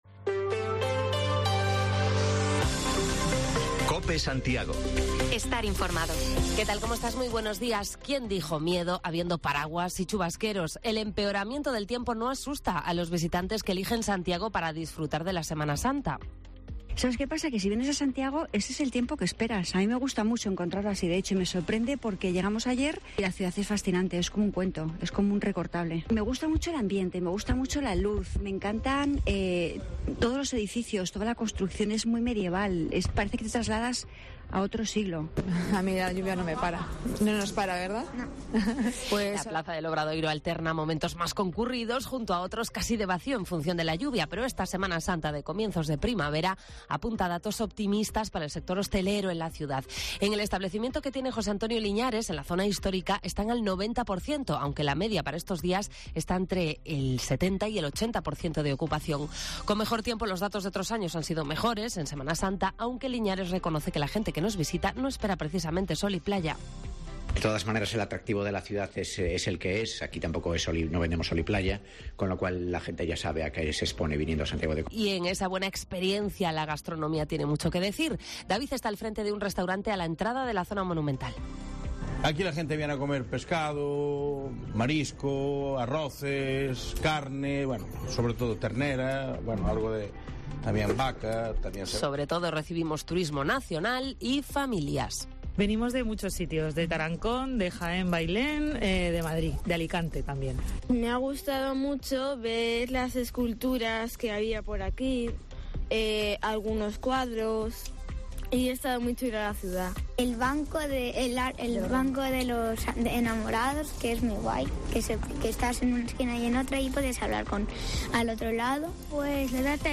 Callejeamos por Santiago en el arranque de Semana Santa para saber de dónde vienen los visitantes que llegan estos días a nuestra ciudad y las previsiones del sector de hostelería.